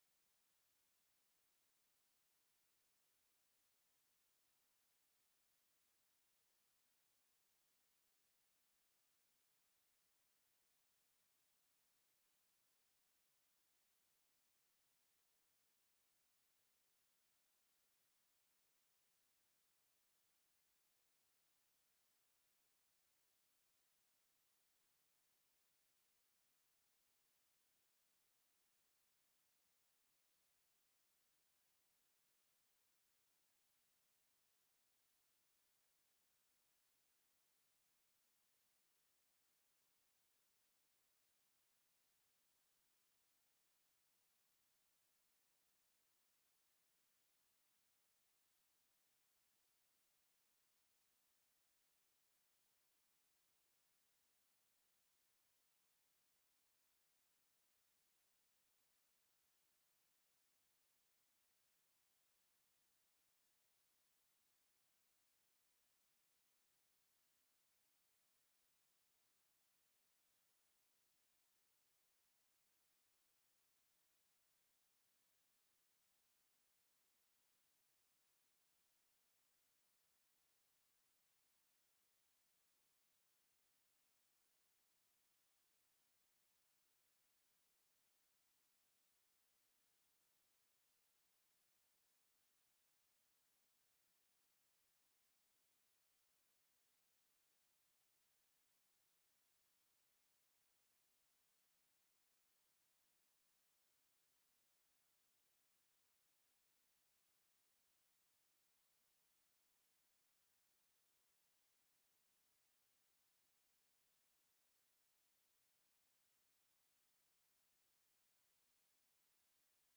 Ps. ljudet är inte det bästa, men det går att höra. ds